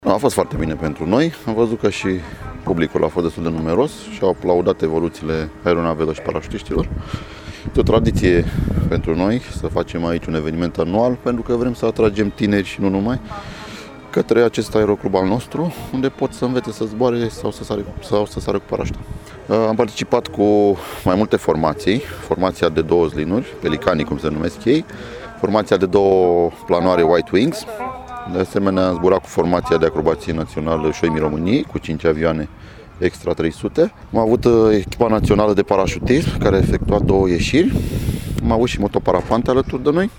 Acestea sunt doar câteva dintre atracțiile celei de-a VII – a ediții a mitingului aviatic „Sky is not the limit”, care a avut loc sâmbătă.